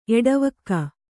♪ eḍavakka